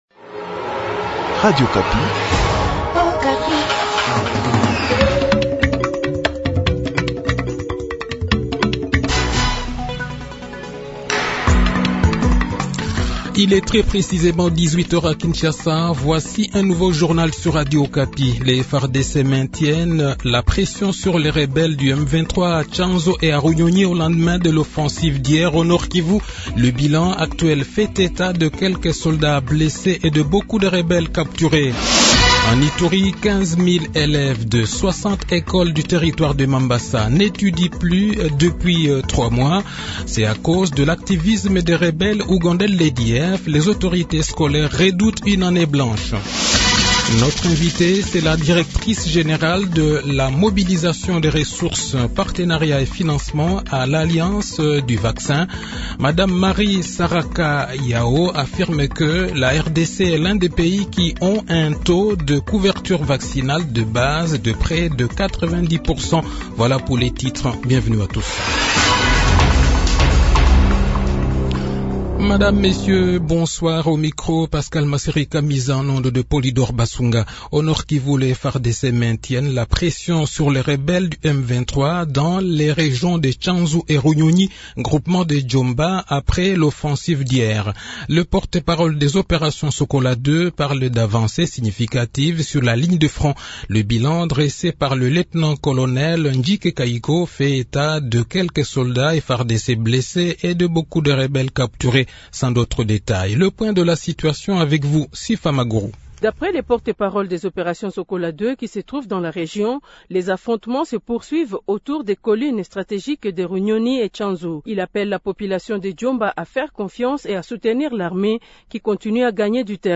Journal Soir
Le journal de 18 h, 7 Avril 2022